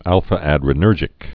(ălfə-ădrə-nûrjĭk)